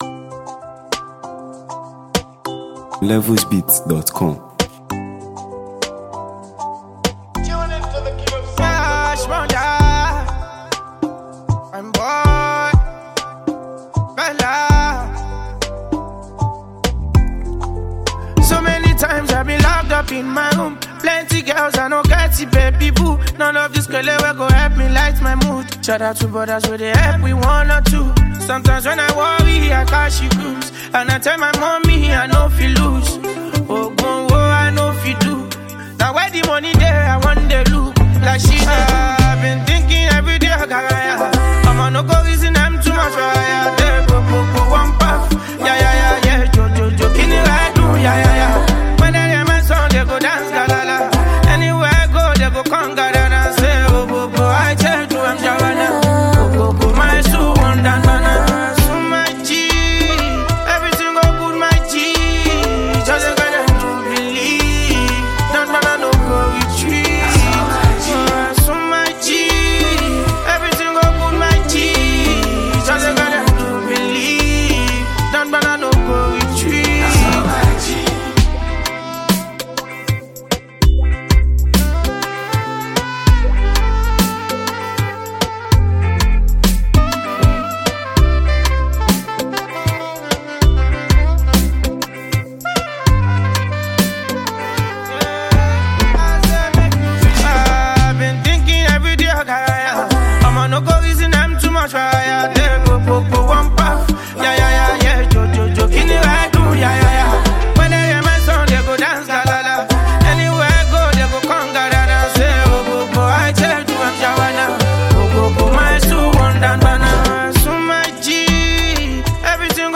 Well-known Nigerian street-pop sensation and songwriter
With its emotional depth and infectious vibe